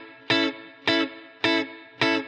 DD_TeleChop_105-Amaj.wav